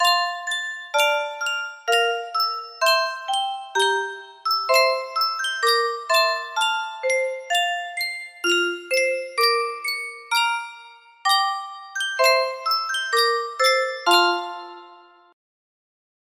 Sankyo Speldosa - Blamann Blamann KSC music box melody
Full range 60